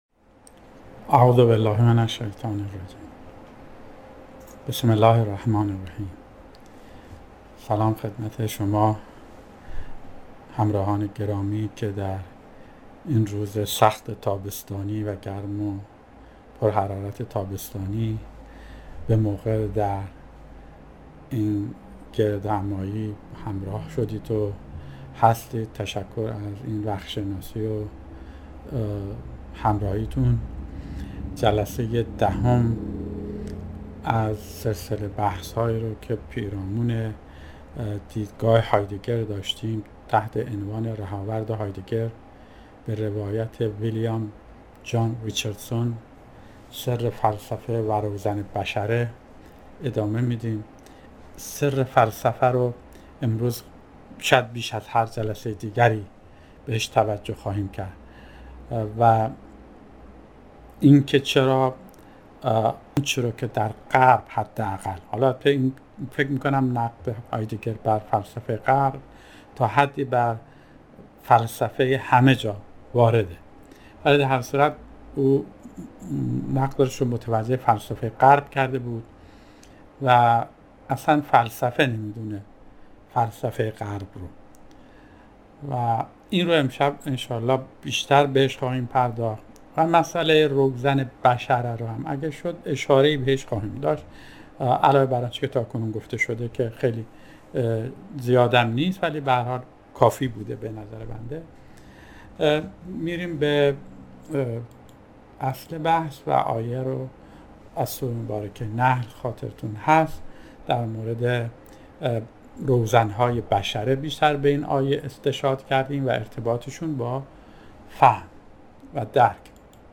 (۲۸ جلسه؛ فارسی) سلسله جلسات بحث و گفتگو به بهانه مصاحبه